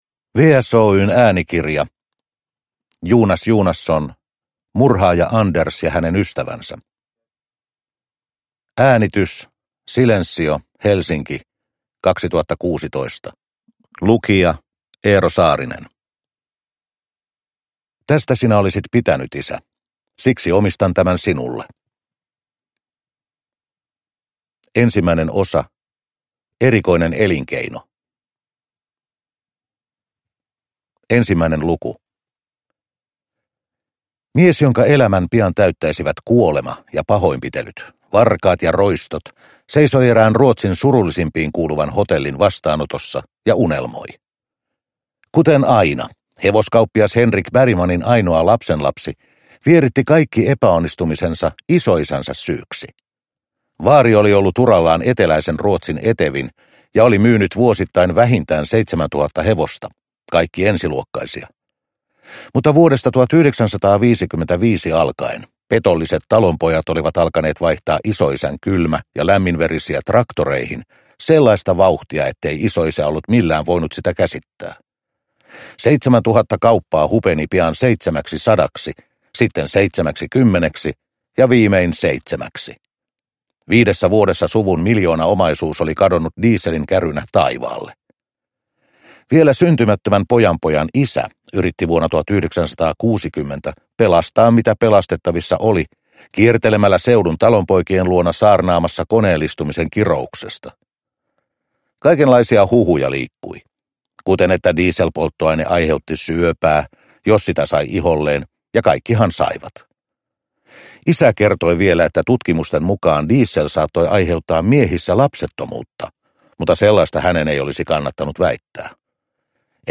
Murhaaja-Anders ja hänen ystävänsä – Ljudbok